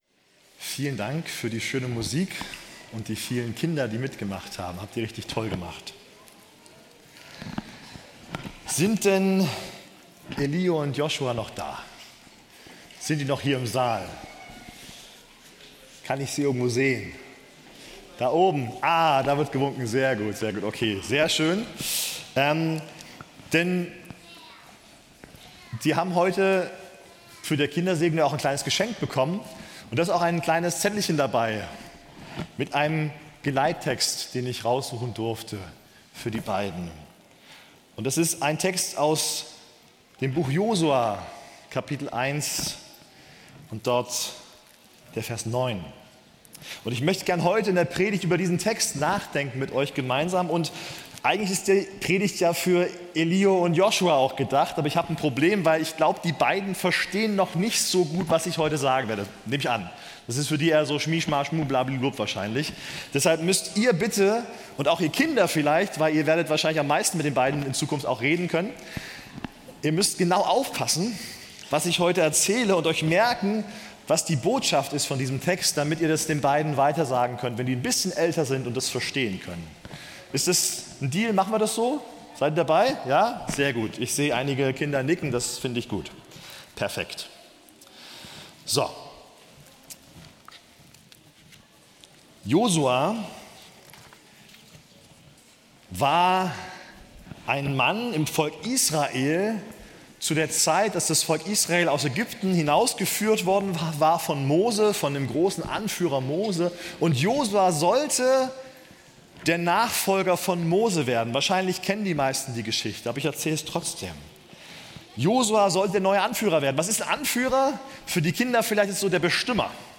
Mitschnitt